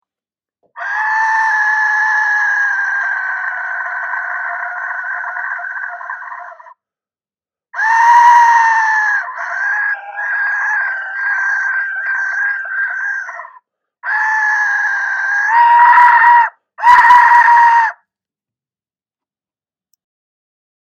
Large Aztec Death Whistle Ancient Mesoamerican Instrument bone white
The Aztec Death Whistle, hand tuned to produce the most frightening scariest sound.
The Aztec Death whistle is a hand crafted musical instrument producing the loudest, scariest, terrifying sound around.
louder.mp3